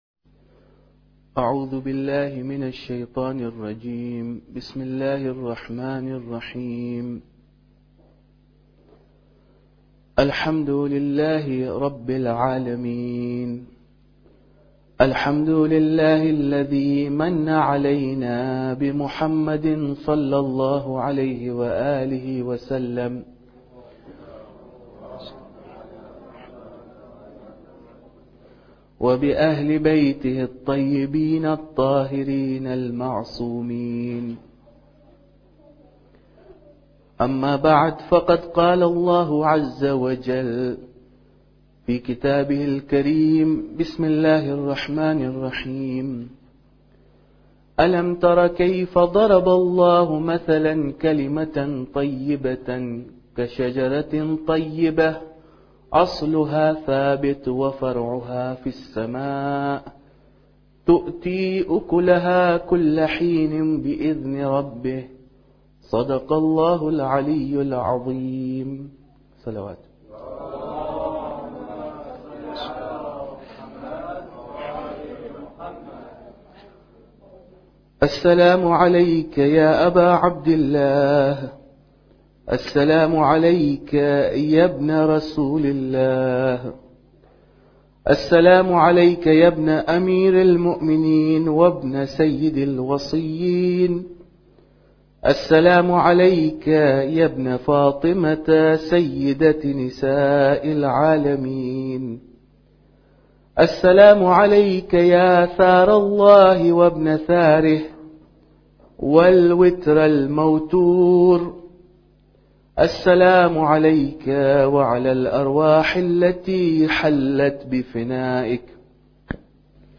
Muharram Lecture 6